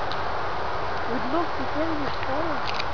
EVP (Electronic Voice Phenomena)
Some examples of EVP recordings (in wav format)